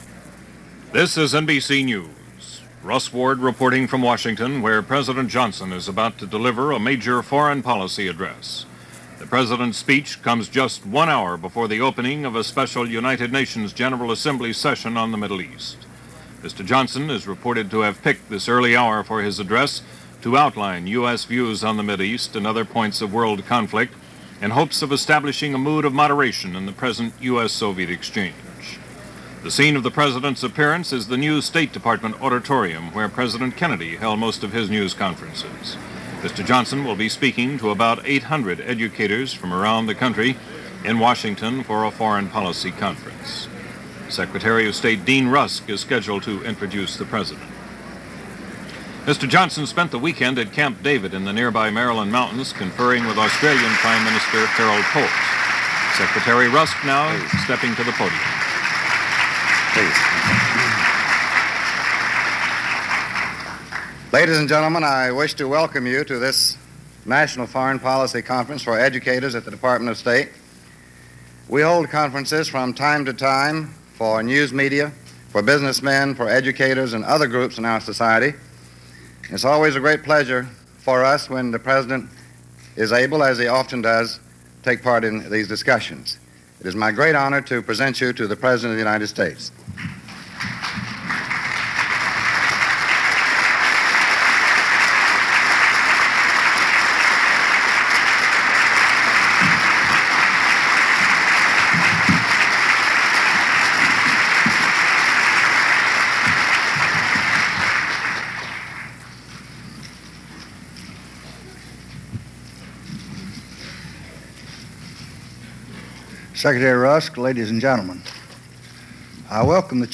In an address before the Department of State Foreign Policy Conference for Educators on June 19, 1967, President Johnson declared that recent events had proved the wisdom of five principles of peace in the Middle East.